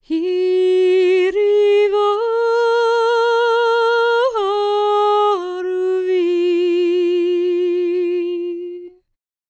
L  MOURN A11.wav